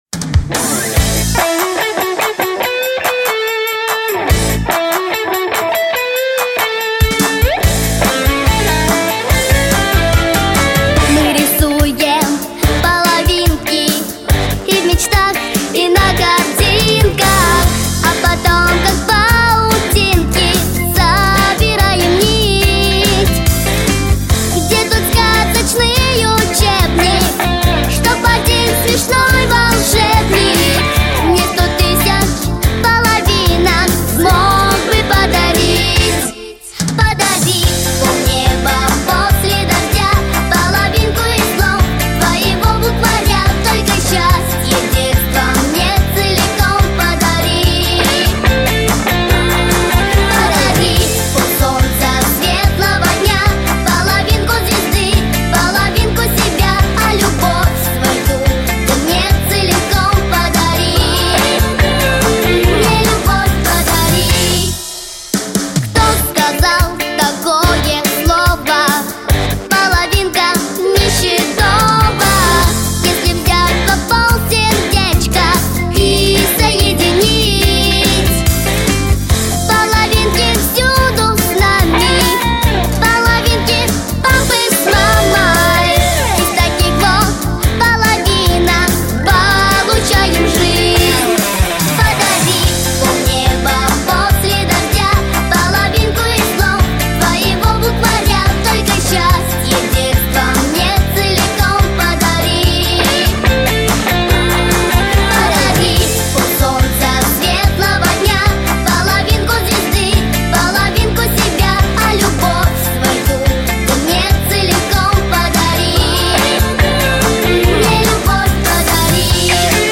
• Категория: Детские песни
Детский эстрадный ансамбль